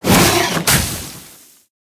Sfx_creature_snowstalkerbaby_flinch_land_01.ogg